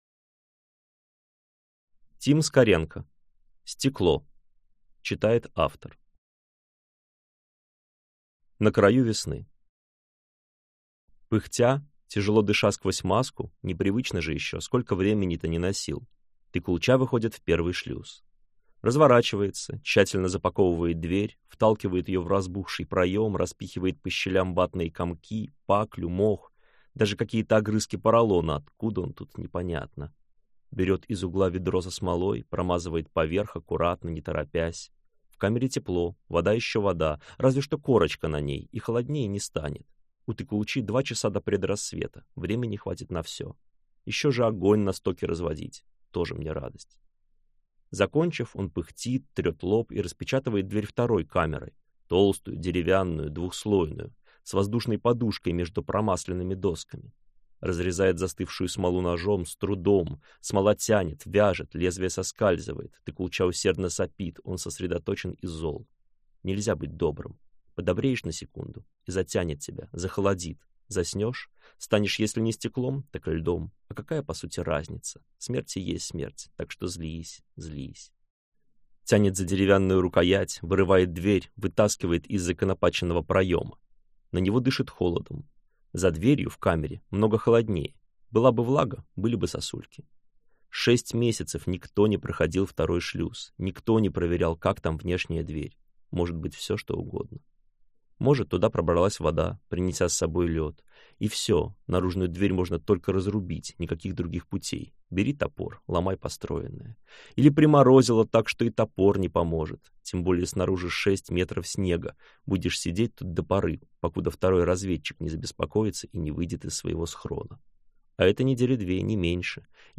Аудиокнига Стекло | Библиотека аудиокниг